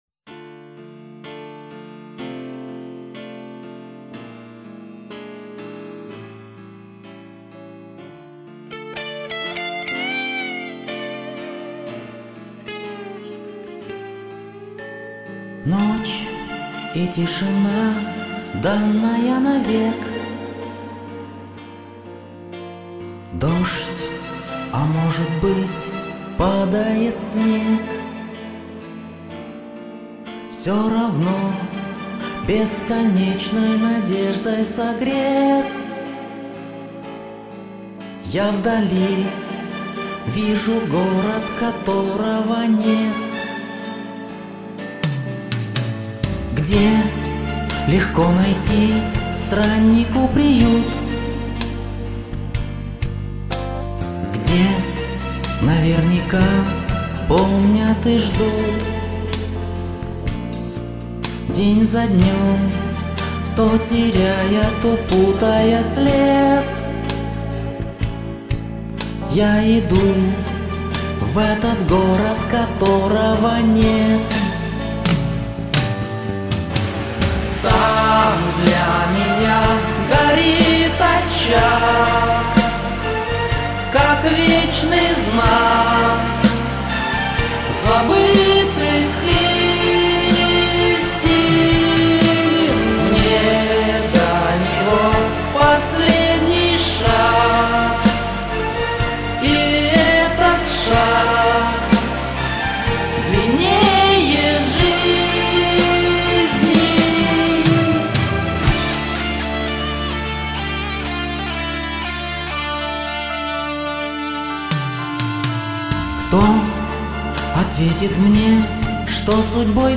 Интересно Вас было послушать... тембры ведь у вас разные!
Песня специфична и звонкому голосу подходит едва ли.